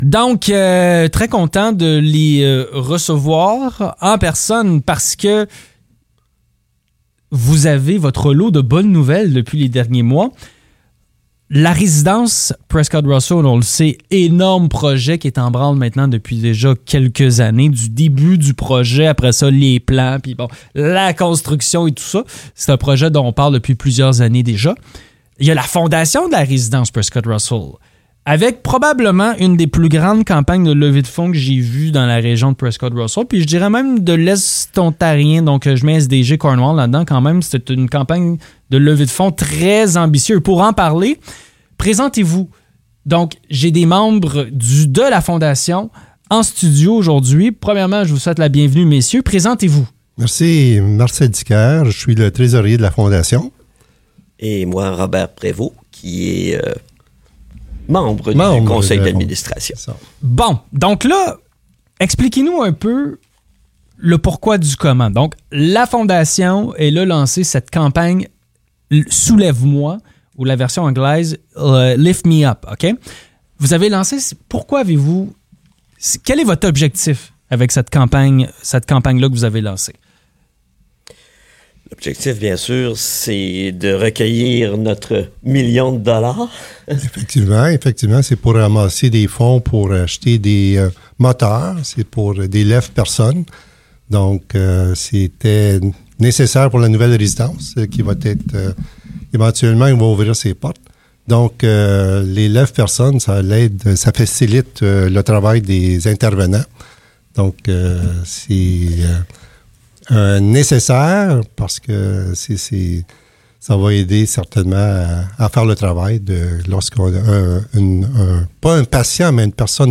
Dans cette entrevue, ils font le point sur l'avancement de cette campagne de financement essentielle pour offrir des soins de qualité aux résidents.